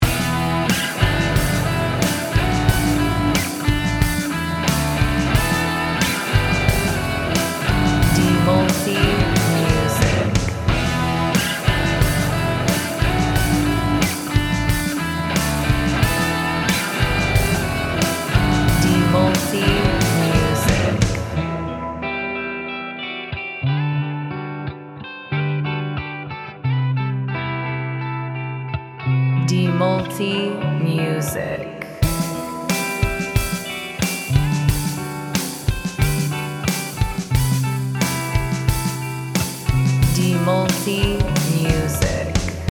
Gym Music Instrumental
Sedikit lebih atmosferik, tapi tetap rock.